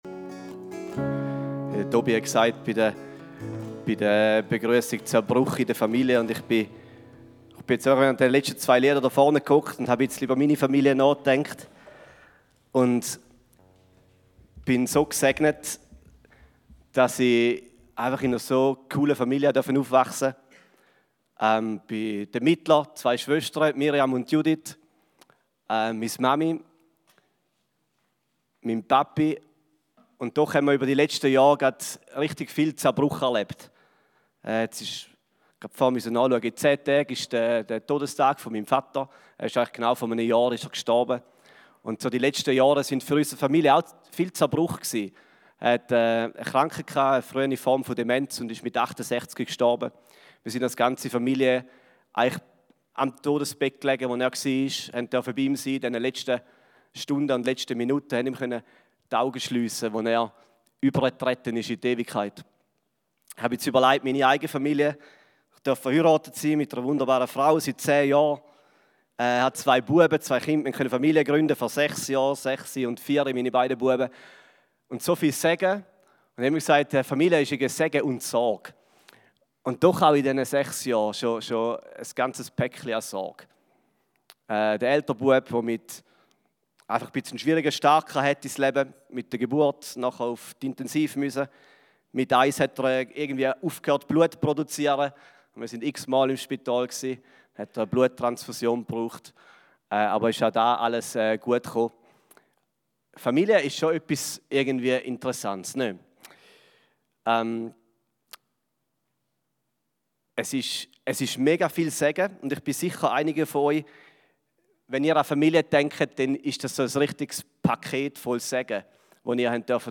Eine predigt aus der serie "RISE & FALL."